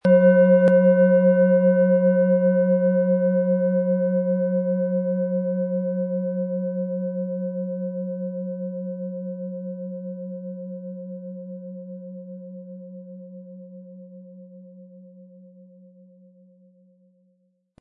• Tiefster Ton: Jupiter
Ein die Schale gut klingend lassender Schlegel liegt kostenfrei bei, er lässt die Planetenklangschale DNA harmonisch und angenehm ertönen.
PlanetentöneDNA & Jupiter
MaterialBronze